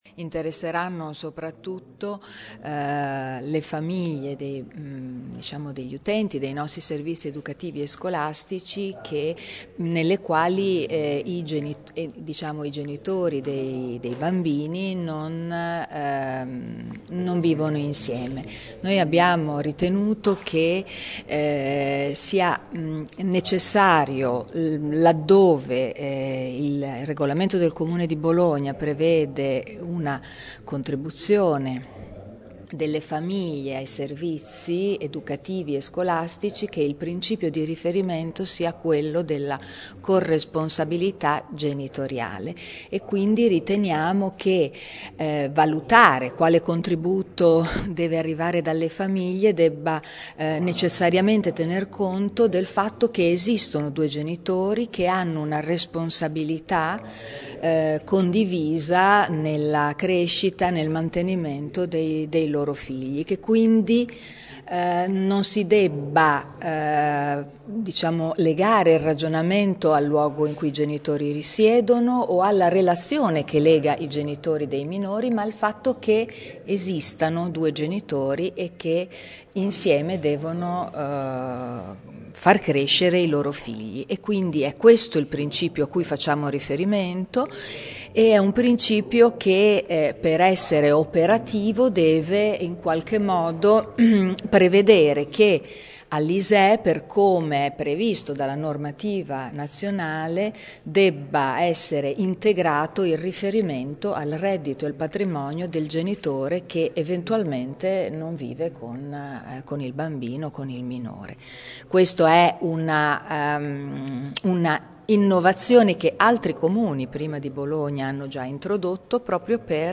Ascolta l’assessore alla scuola, Marilena Pillati